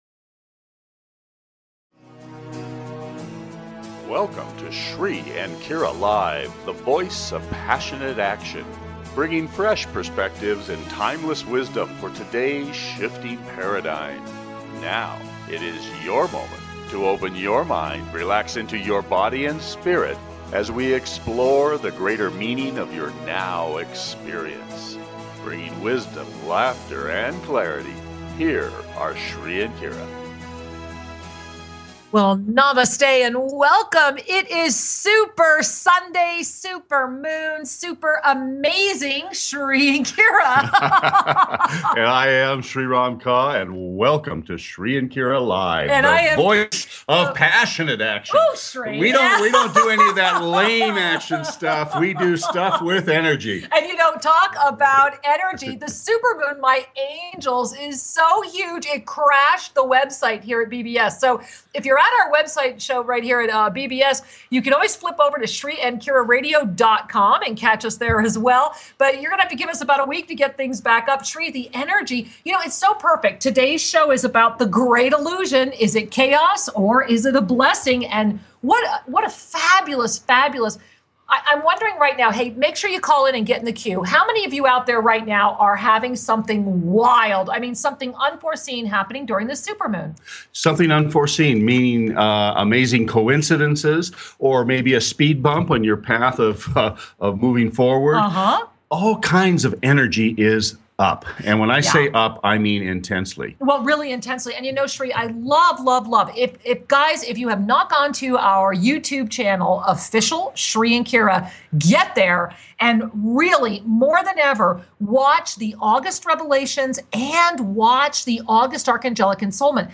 An illuminating program with thought provoking depth and the phone lines are open!